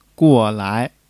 guo4-lai2.mp3